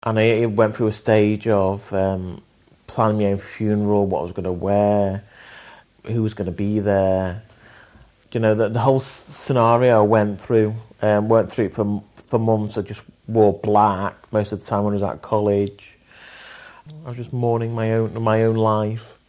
Living Stories: audio interviews with people living with haemophilia who were diagnosed with HIV